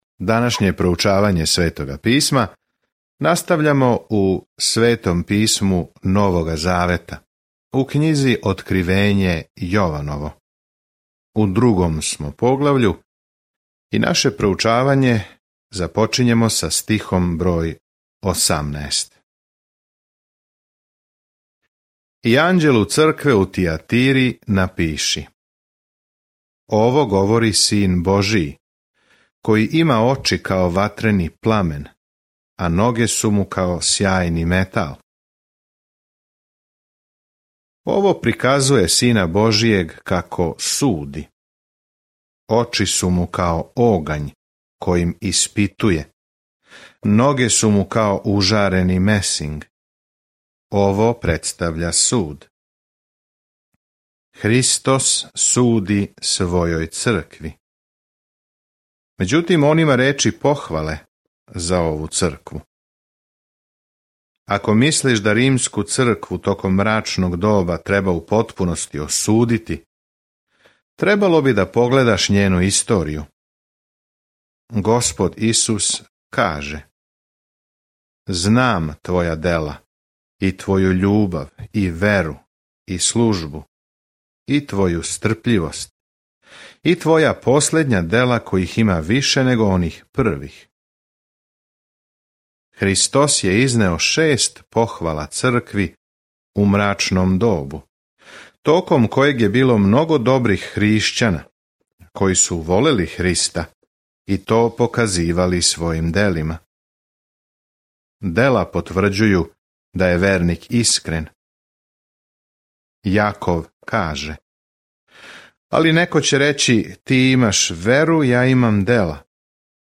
Scripture Revelation 2:18-29 Revelation 3:1 Day 14 Start this Plan Day 16 About this Plan Откривење бележи крај свеобухватне временске линије историје са сликом о томе како ће се коначно обрачунати са злом и како ће Господ Исус Христ владати у свакој власти, моћи, лепоти и слави. Свакодневно путовање кроз Откривење док слушате аудио студију и читате одабране стихове из Божје речи.